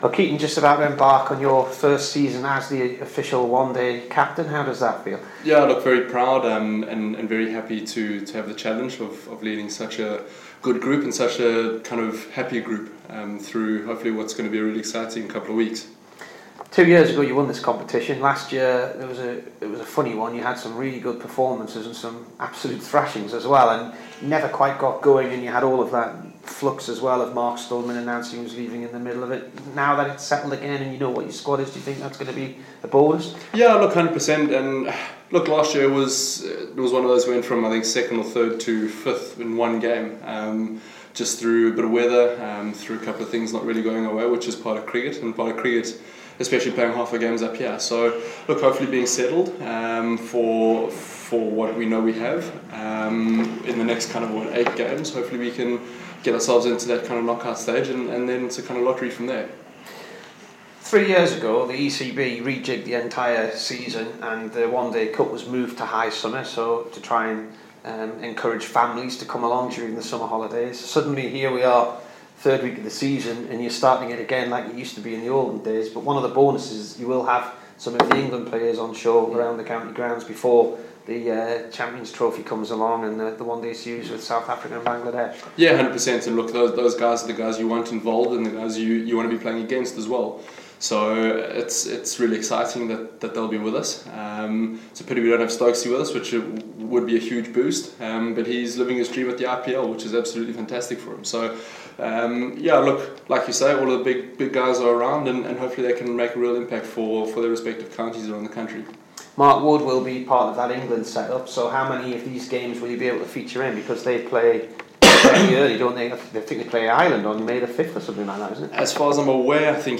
KEATON JENNINGS INT